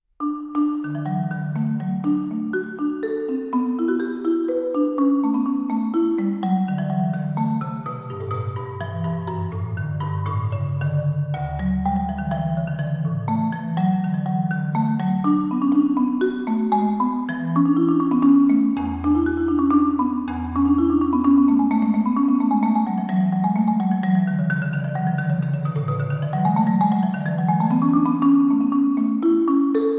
Marimba and Vibraphone